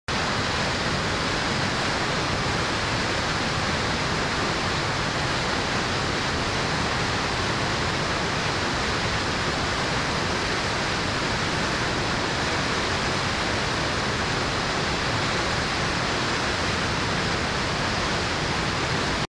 機械のモーター音やアナウンス、発車の合図......。地下鉄、都電荒川線、都営バスのそれぞれの場所でしか聞くことができない音を収録しました。
第15回白丸調整池ダム「ゲート放流時の音」